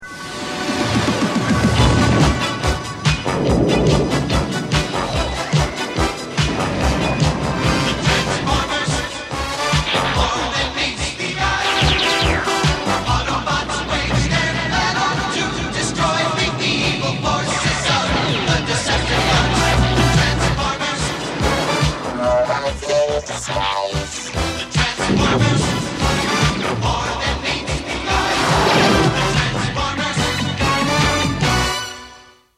Canción de la secuencia de título